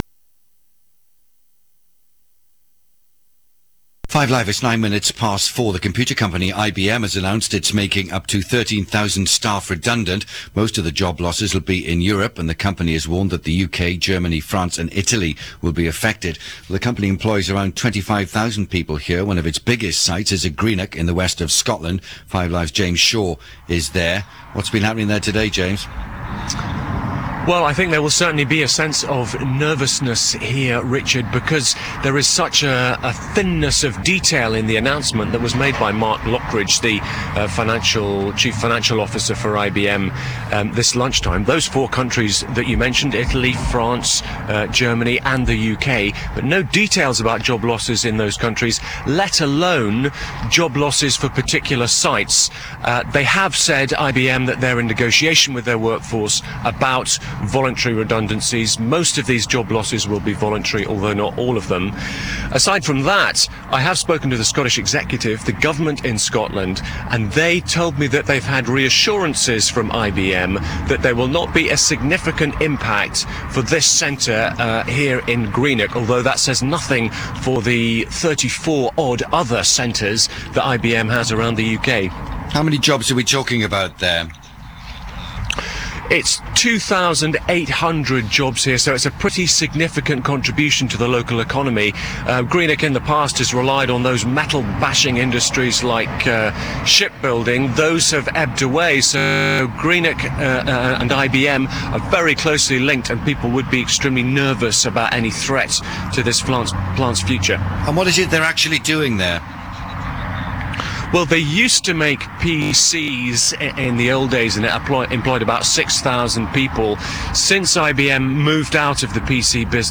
Caution - a 14MB .WAV file digitised from the BBC's streaming audio. This interview was given with about eight minutes' warning on the subject of IBM's announcement of 13,000 redundancies world wide and a major reorganisation of its European operations.